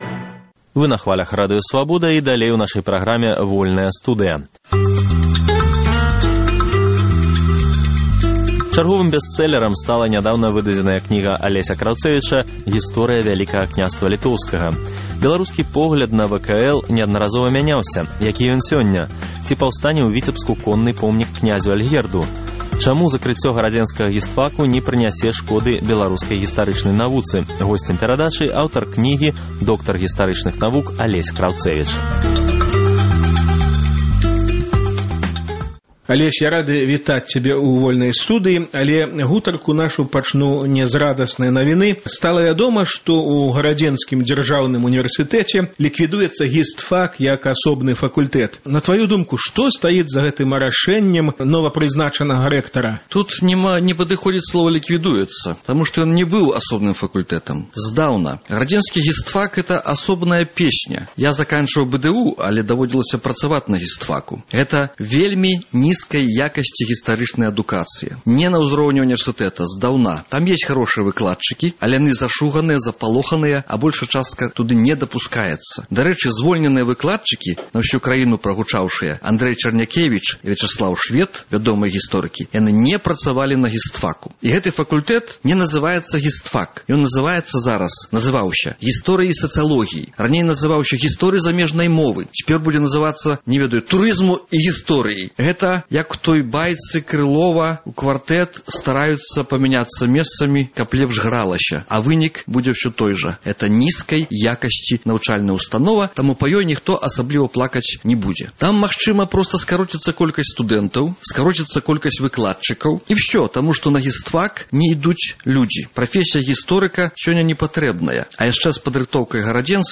Беларускі погляд на ВКЛ. Гутарка